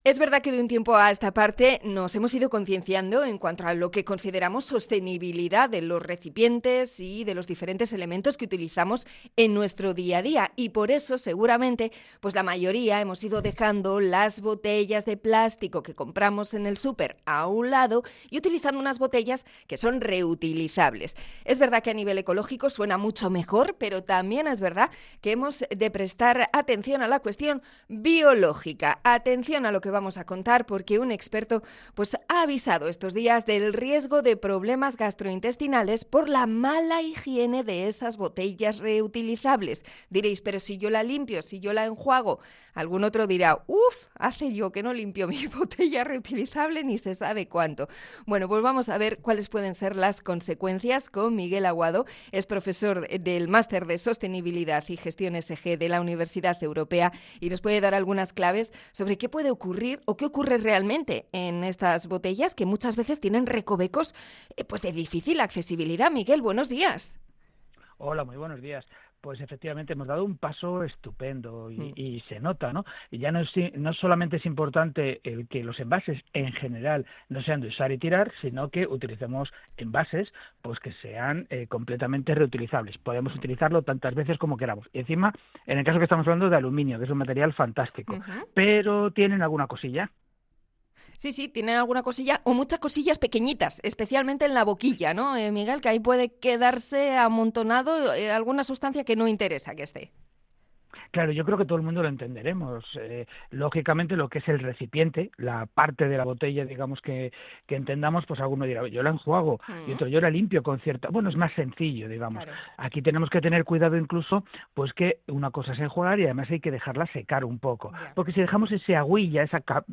Entrevista sobre las botellas reutilizables